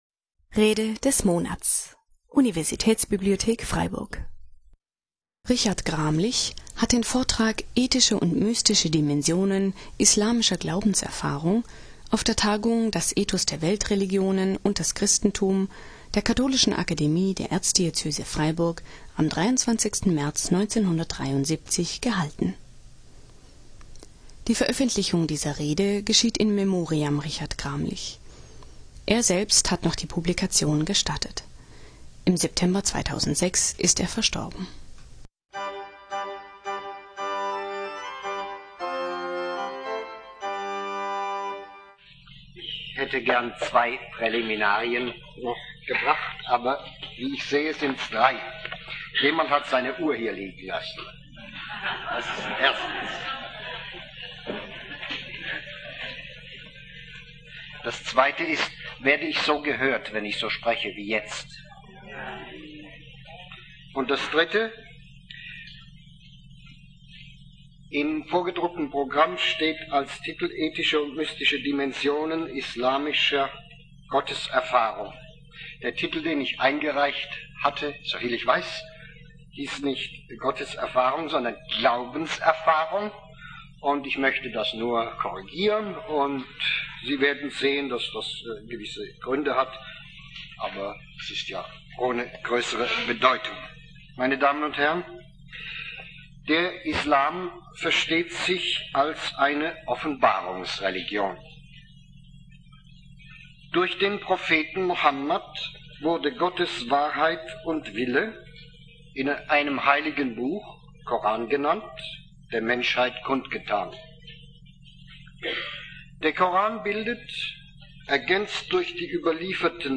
Ethische und mystische Dimensionen islamischer Glaubenserfahrung (1973) - Rede des Monats - Religion und Theologie - Religion und Theologie - Kategorien - Videoportal Universität Freiburg